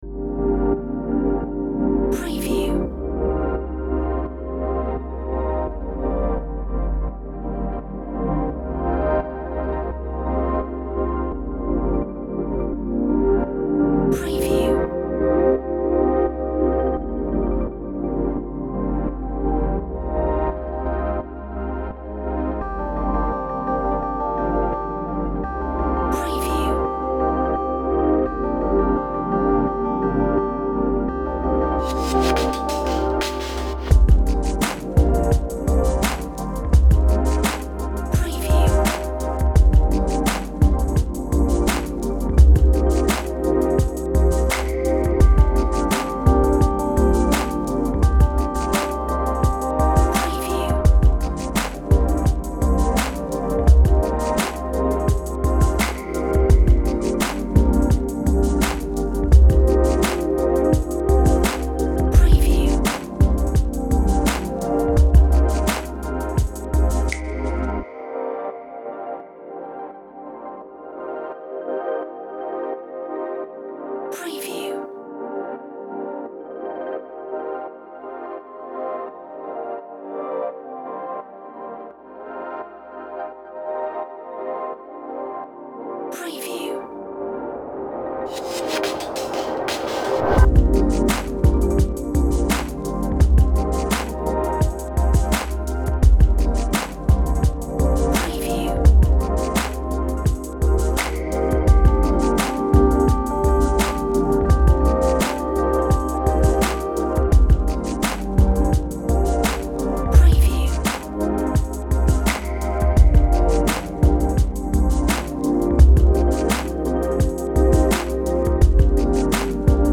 Relaxing Vibes